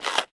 eat1.ogg